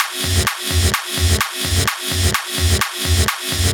VEH1 Fx Loops 128 BPM
VEH1 FX Loop - 09.wav